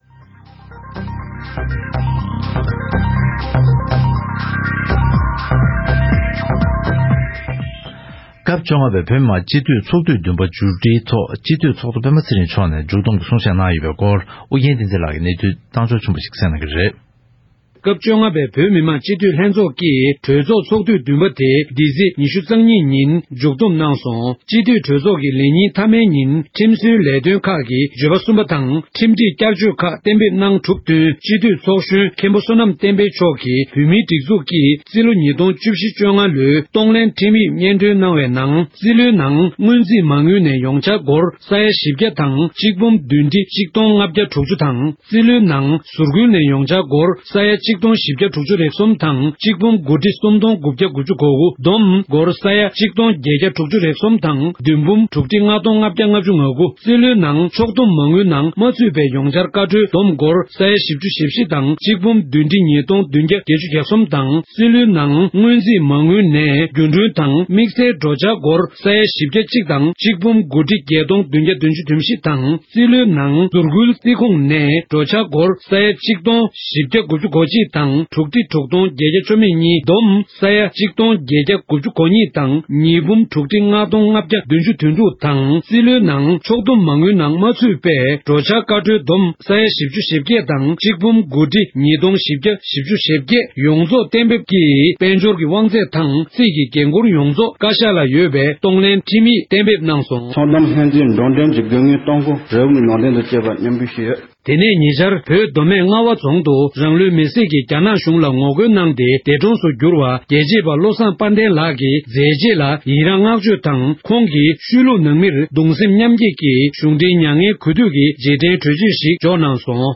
ས་གནས་ནས་བཏང་བའི་གནས་ཚུལ་ལ་གསན་རོགས།